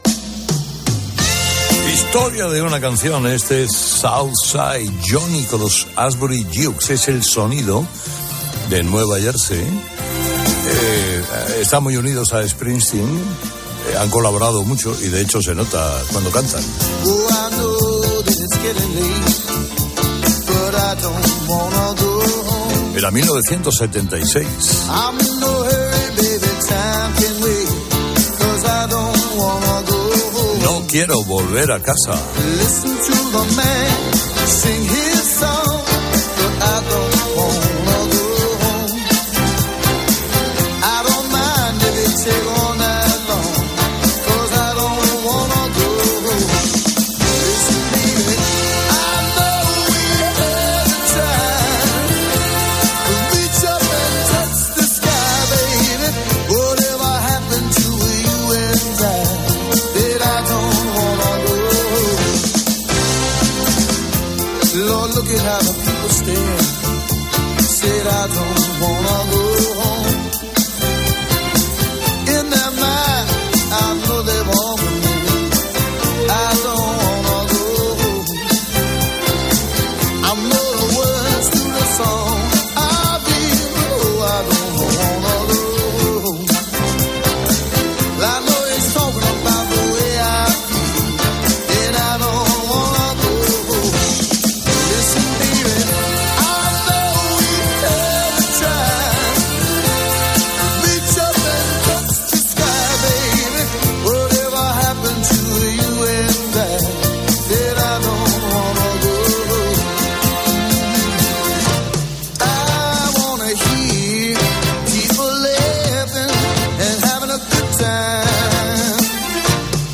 Ese es el sonido de Nueva Jersey.